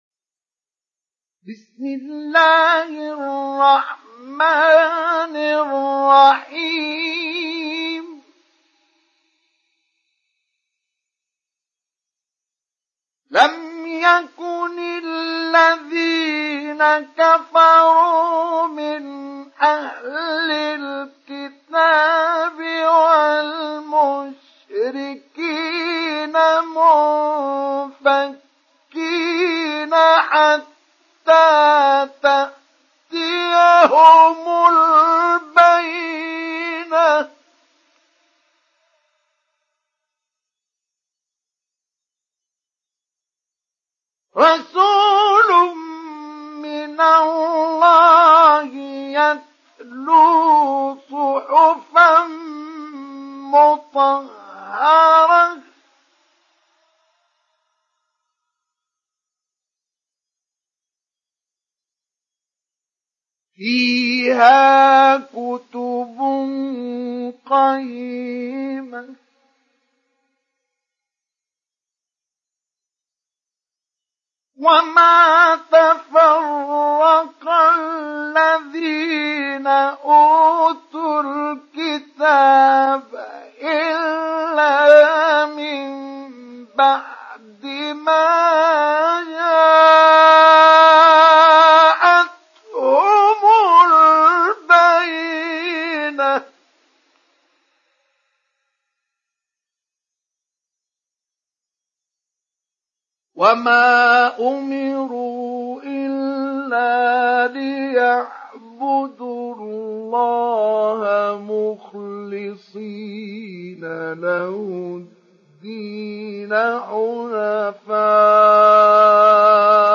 Surah Al Bayyinah Download mp3 Mustafa Ismail Mujawwad Riwayat Hafs from Asim, Download Quran and listen mp3 full direct links
Download Surah Al Bayyinah Mustafa Ismail Mujawwad